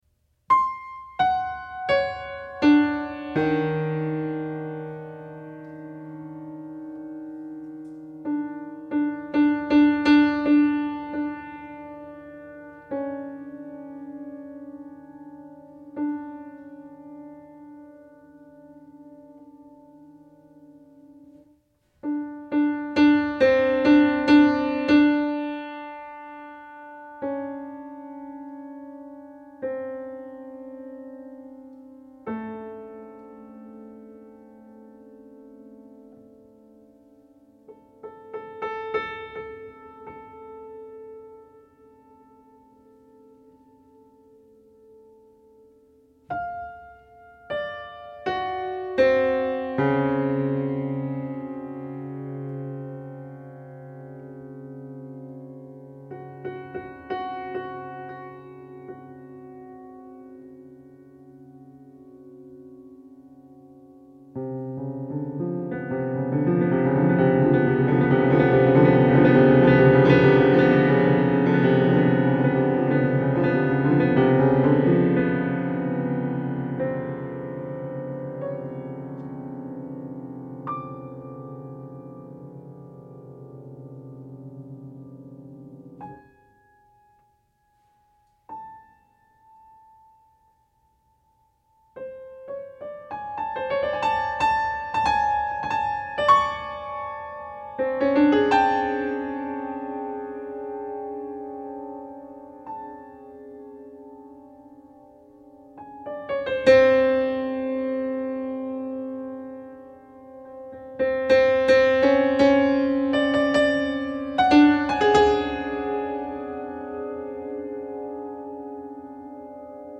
Para piano solo.